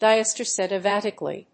/ˌdaɪəstɛəɹioʊsəlɛkˈtɪvɪti(米国英語)/